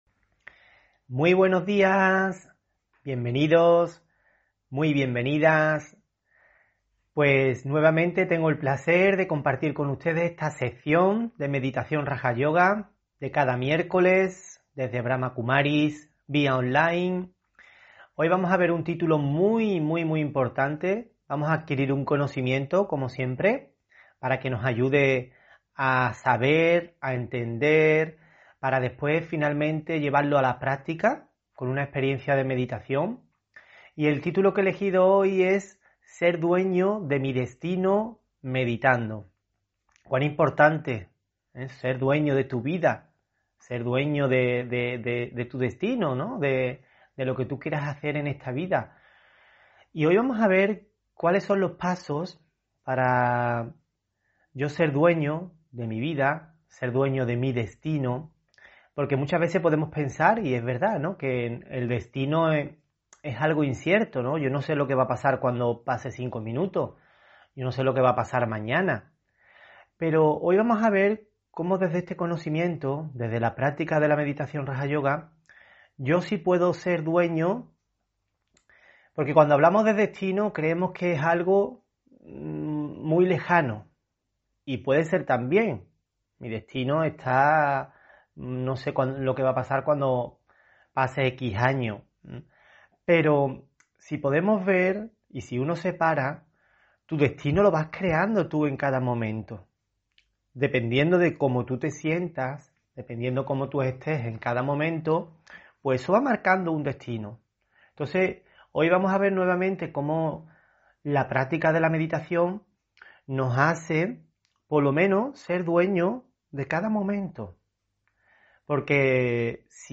Meditación Raja Yoga y charla: Ser dueño de mí destino meditando (28 Julio 2021) On-line desde Sevilla